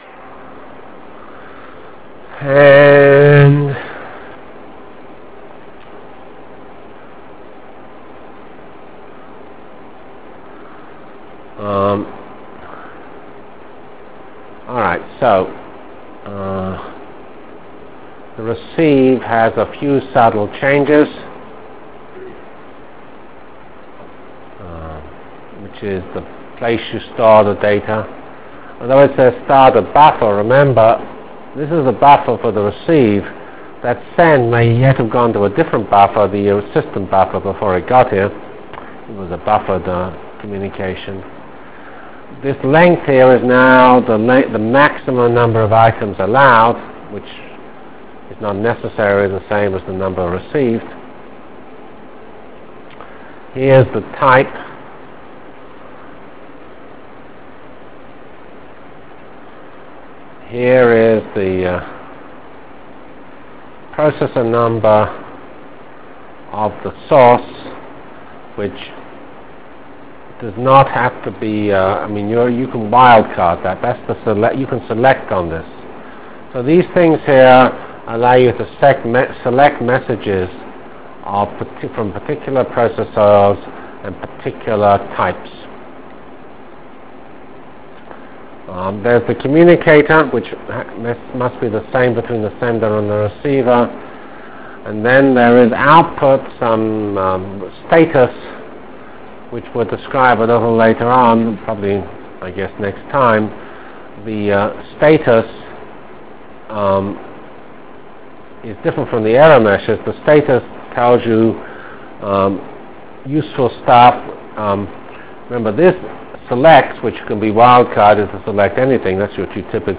From CPS615-Initial Lecture on MPI ending with discussion of basic MPI_SEND Delivered Lectures of CPS615 Basic Simulation Track for Computational Science -- 31 October 96.